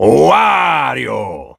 17 KB {{aboutfile |1=Wario exclaims his name from ''Super Mario Party Jamboree''.